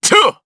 DarkKasel-Vox_Attack2_jp.wav